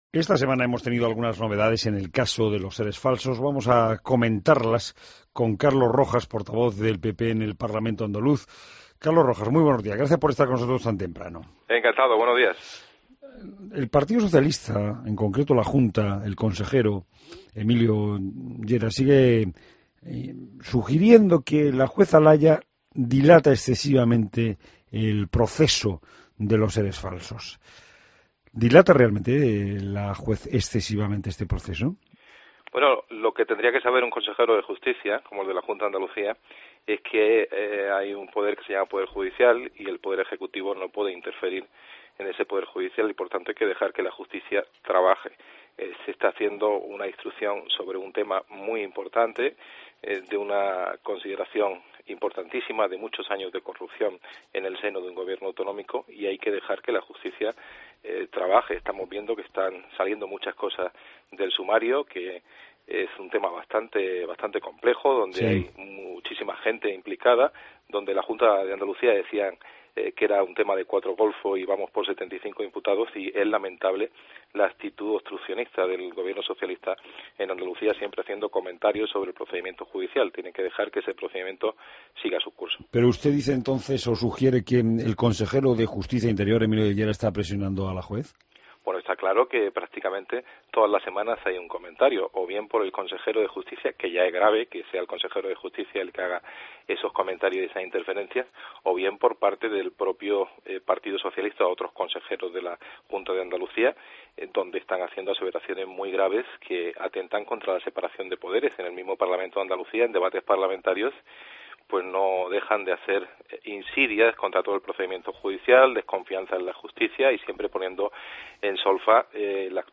Entrevista a Carlos Rojas, portavoz del PP andaluz